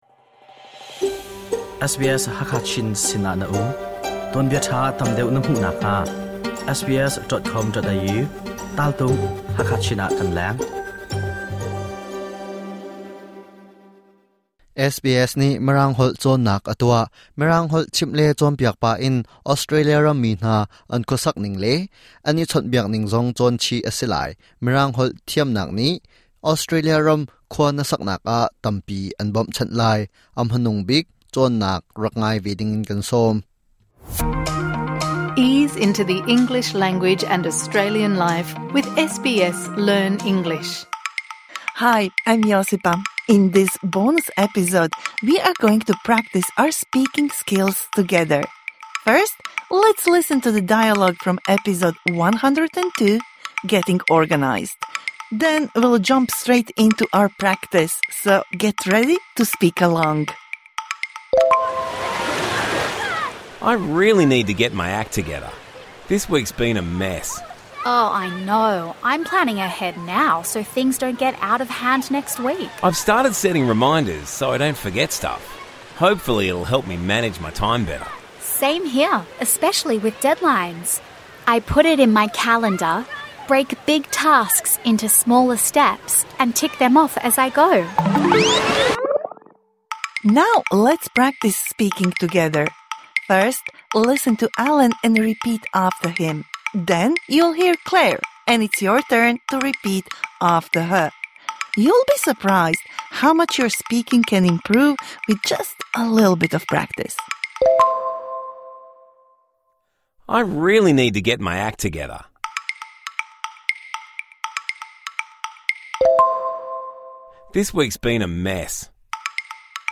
This bonus episode provides interactive speaking practice for the words and phrases you learnt in #102 Getting organised (Med)